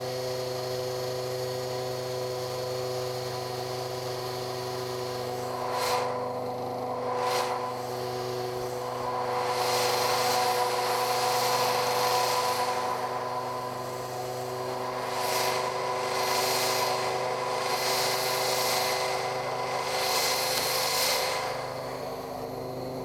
Recorded with zoom H6, stereo mic with parabolic reflector
Machines